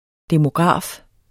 Udtale [ demoˈgʁɑˀf ]